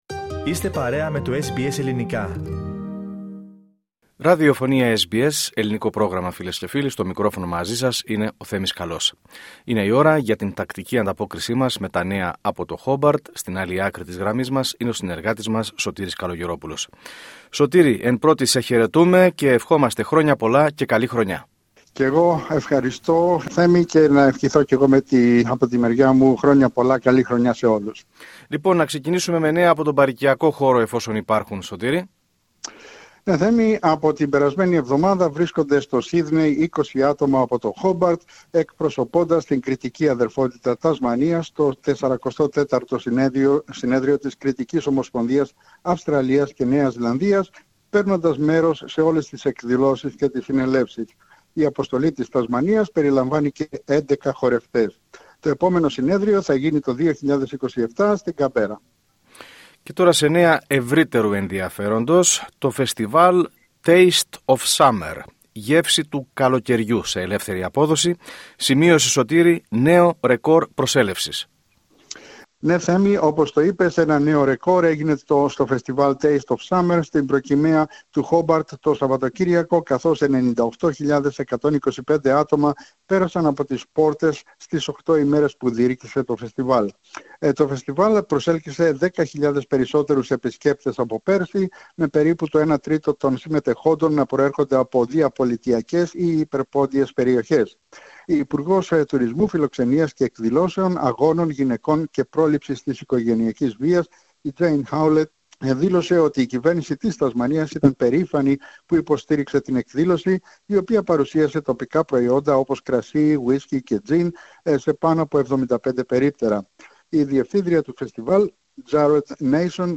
Ακούστε την εβδομαδιαία ανταπόκριση από την Τασμανία